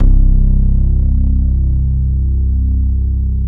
35BASS01  -R.wav